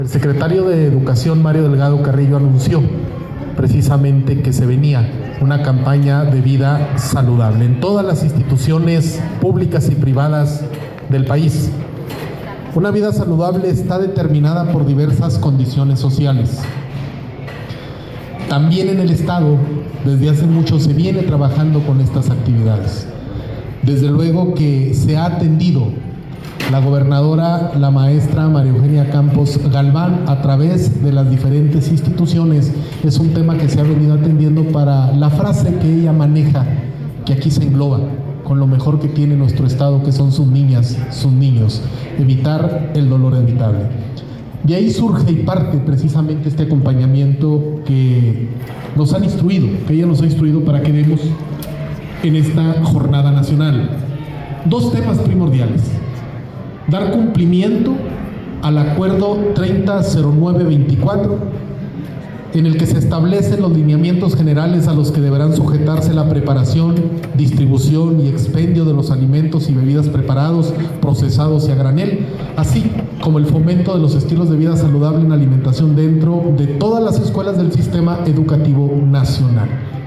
Durante la ceremonia de arranque, el subsecretario de Educación Básica, Lorenzo Arturo Parga Amado, destacó que el programa busca, además, inculcar el estilo de vida saludable en los planteles públicos y privados de México, una iniciativa que también ha sido impulsada por la actual administración estatal.